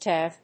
/ˈɛˈmtiˈvi(米国英語), ˈeˈmti:ˈvi:(英国英語)/